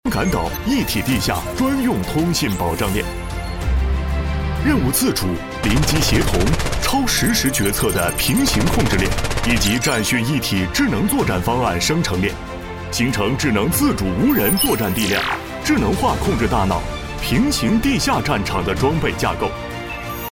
B男11号 | 声腾文化传媒
【专题】军事题材 严肃激情 未来地下无人
【专题】军事题材 严肃激情 未来地下无人.mp3